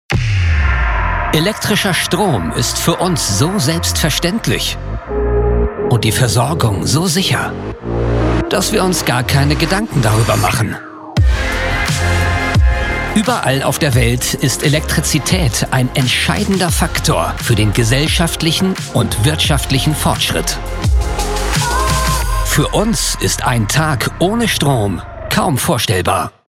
Commercieel, Stoer, Volwassen, Vriendelijk, Zakelijk
Corporate